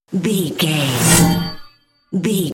Sci fi appear whoosh debris
Sound Effects
Atonal
futuristic
intense
whoosh